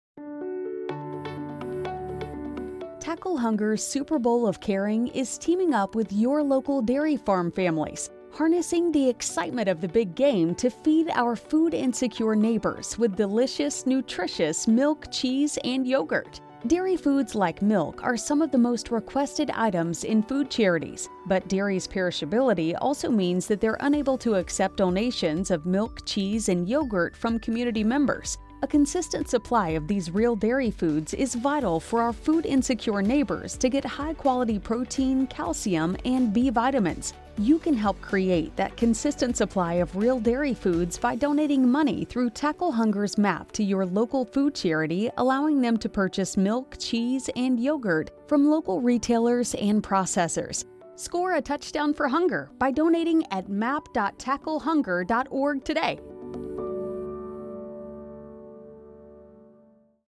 Dairy PSA